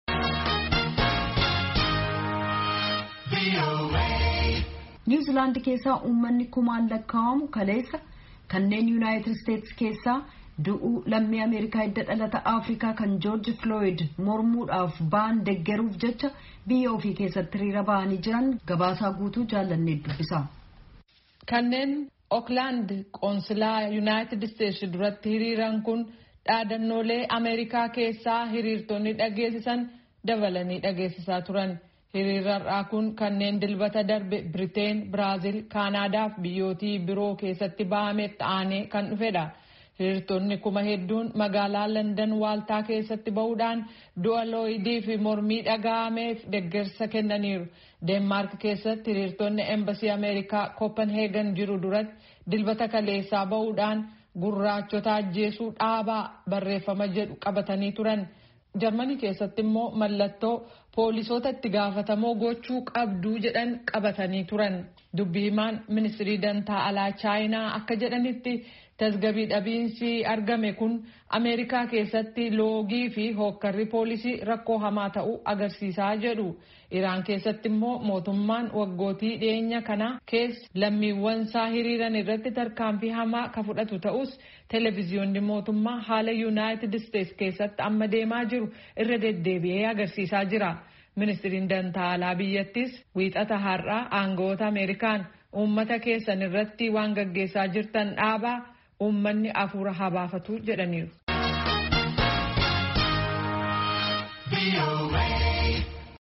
Gabaasa guutuu